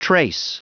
Prononciation du mot trace en anglais (fichier audio)
Prononciation du mot : trace